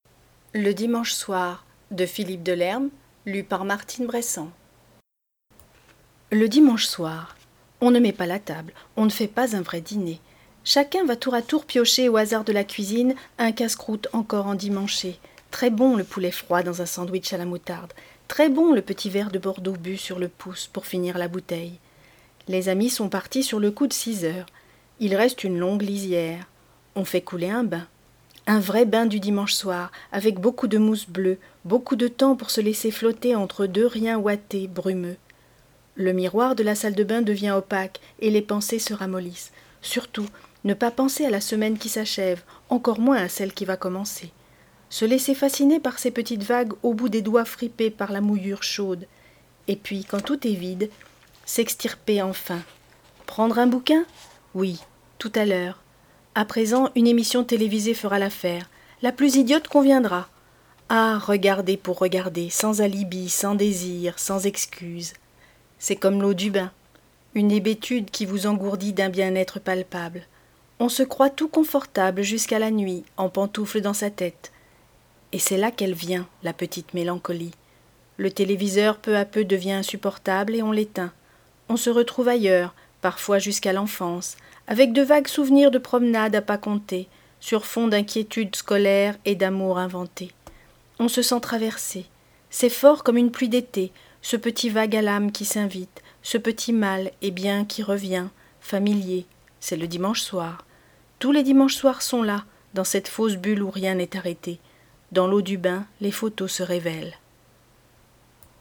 Lecture à haute voix -Le dimanche soir